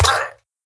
Minecraft Version Minecraft Version 25w18a Latest Release | Latest Snapshot 25w18a / assets / minecraft / sounds / mob / villager / death.ogg Compare With Compare With Latest Release | Latest Snapshot
death.ogg